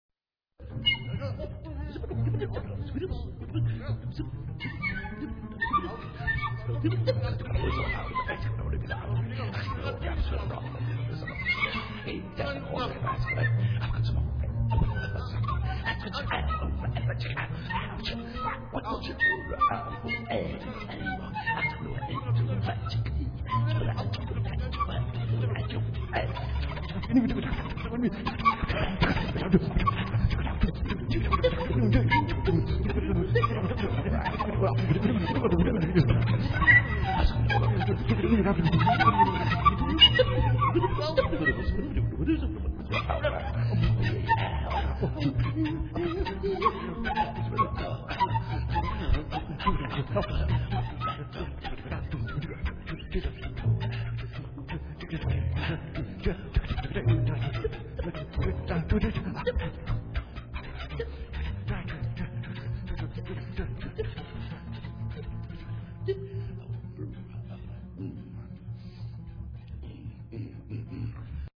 Improvisation # 2